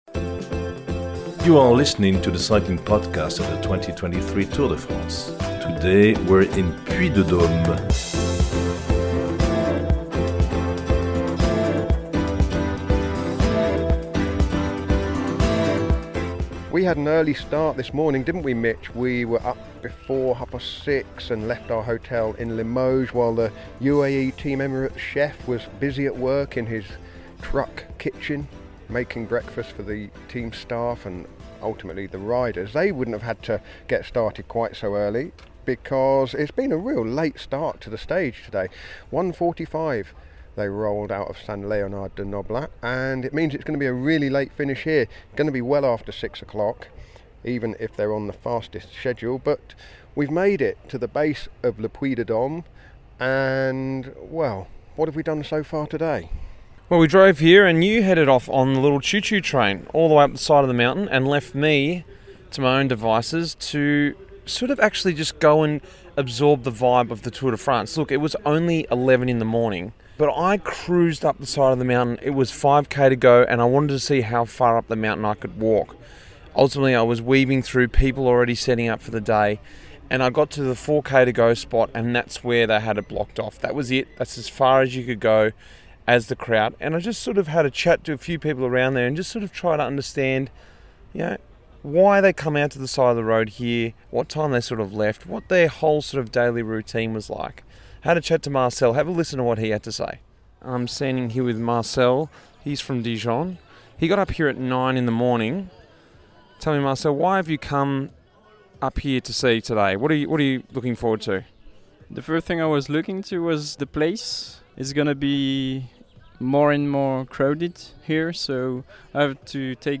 at Le Puy de Dôme as they recap stage nine of the Tour de France.